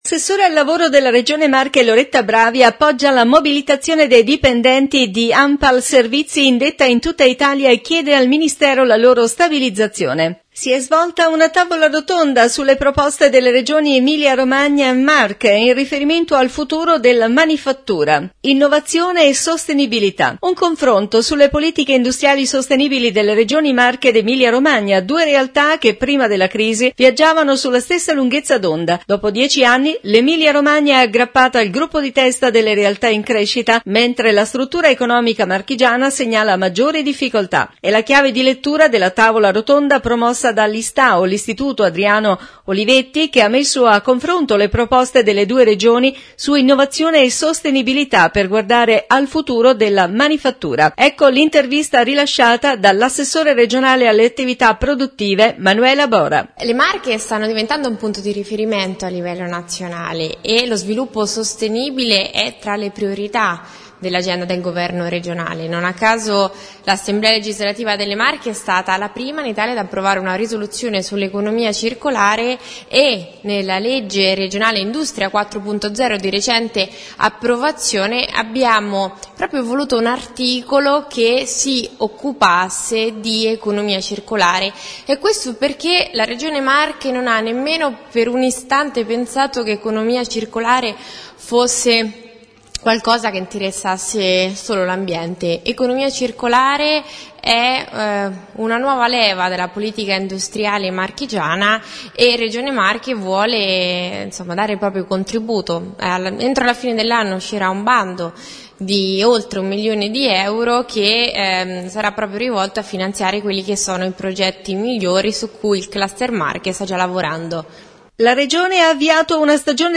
Marche in Movimento festa finale Int. :L. Ceriscioli – Pres. Regione Marche